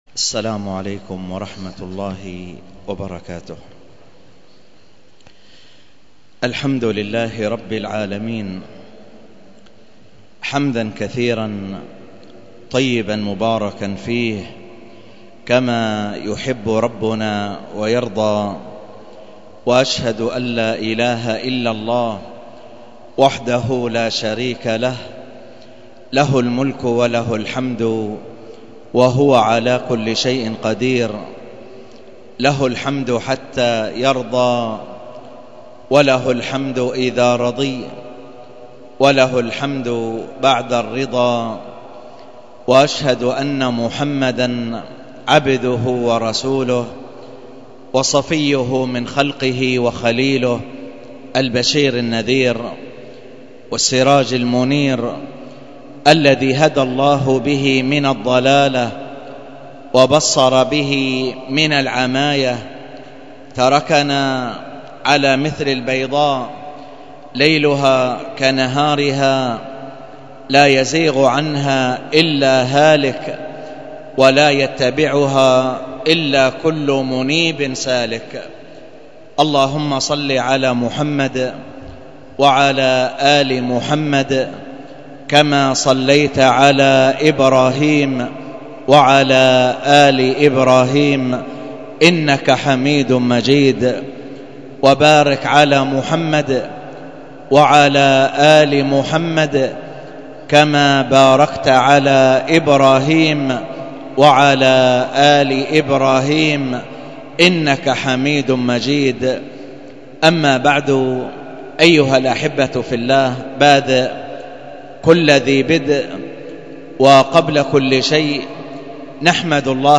الدرس في الصحيح المسند من دلائل النبوة 63، الدرس الثالث والستون:من( قال الإمام البخاري رحمه الله:حدثنا قتيبة حدثنا الليث عن سعيد ...